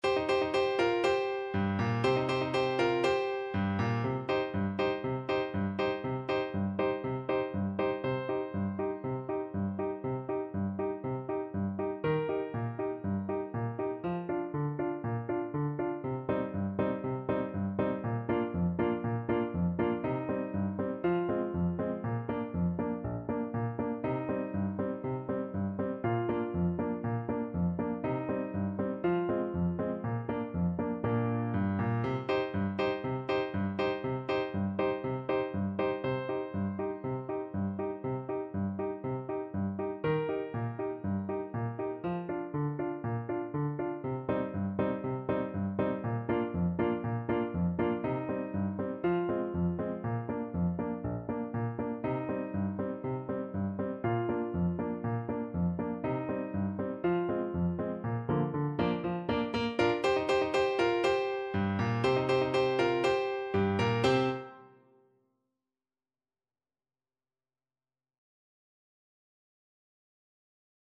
Traditional Music of unknown author.
~ = 120 Allegro (View more music marked Allegro)